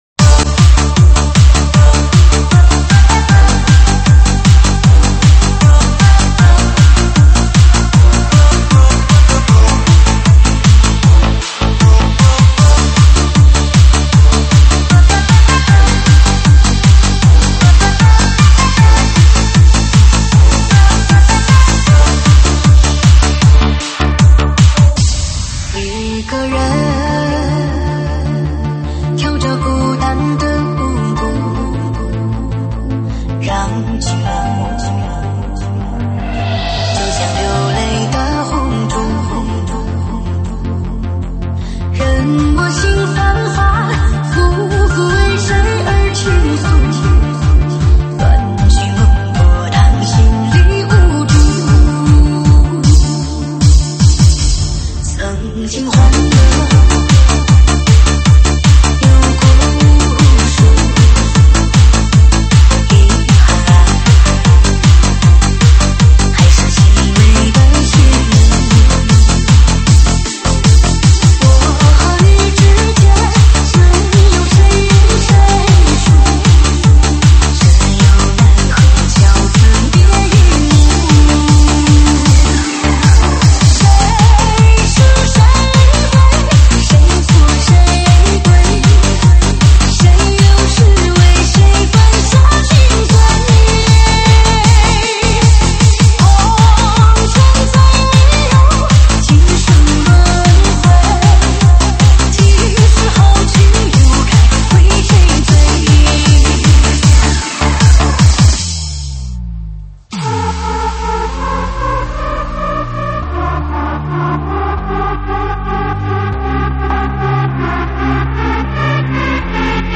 舞曲编号：80883